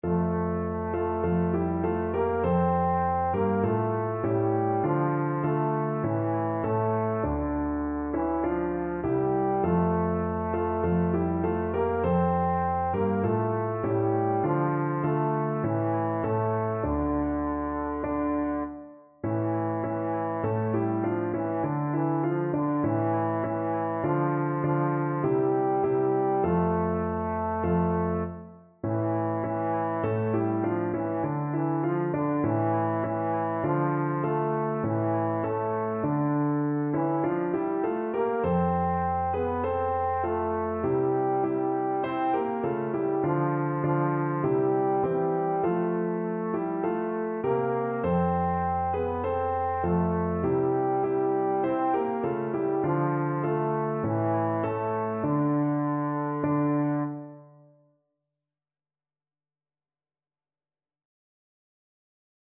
Traditional Trad. Shche ne Vmerla Ukrayiny ni Slava, ni volya (Ukrainian National Anthem) Trombone version
Trombone
F major (Sounding Pitch) (View more F major Music for Trombone )
4/4 (View more 4/4 Music)
Traditional (View more Traditional Trombone Music)
ukraine_national_TBNE.mp3